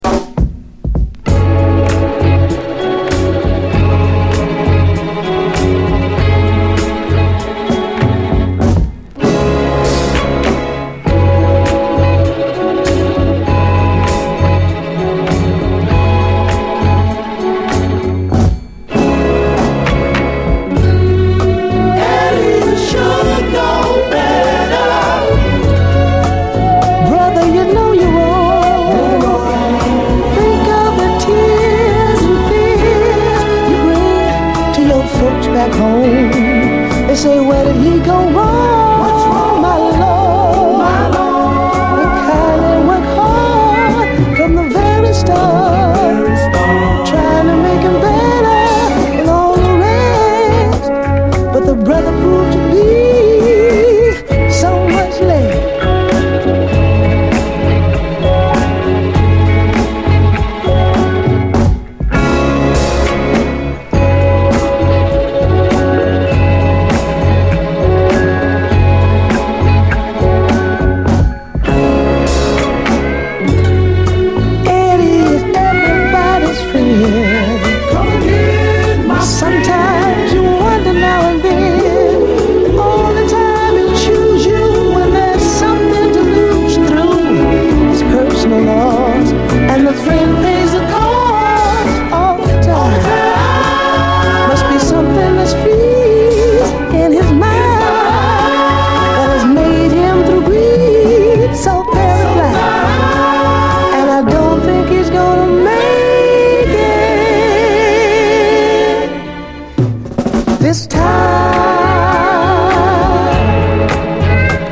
SOUL, 70's～ SOUL, 7INCH
イントロのストリングスがゾクゾクさせます！
STEREO / MONO プロモ、U.S.ORIGINAL盤！